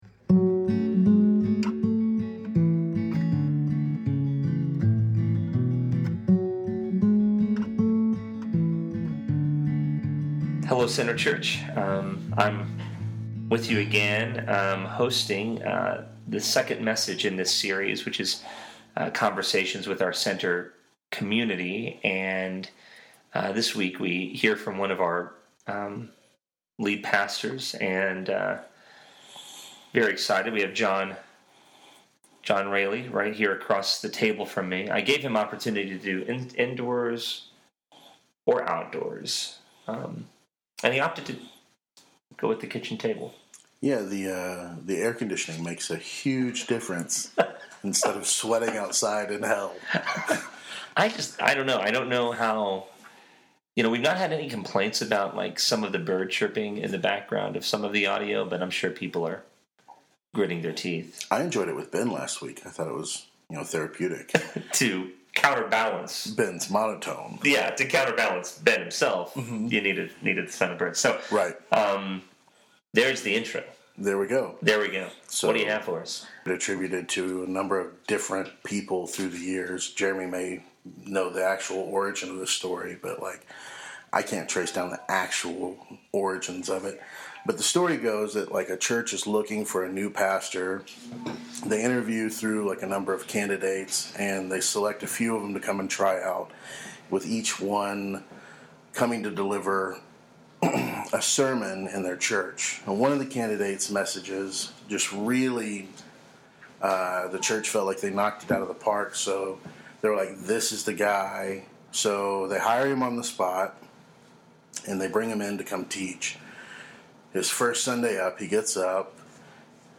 Love | A Conversation